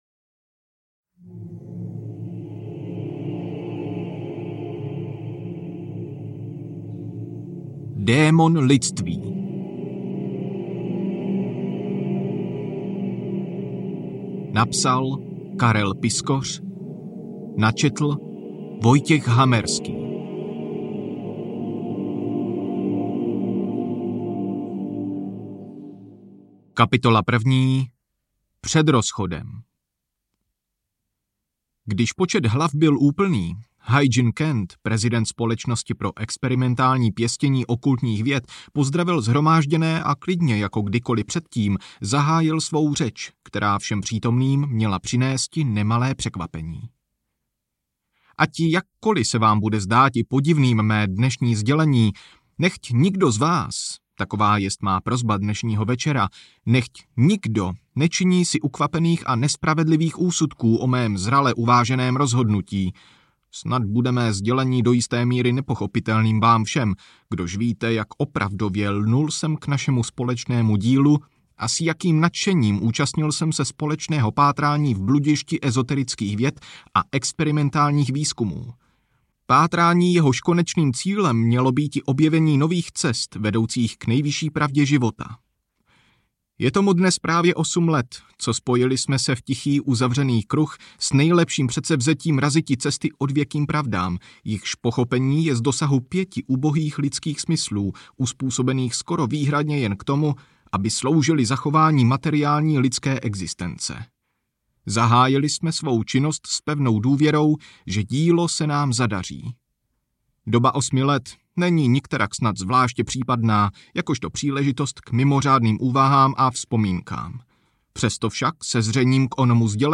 Démon lidství audiokniha
Ukázka z knihy
demon-lidstvi-audiokniha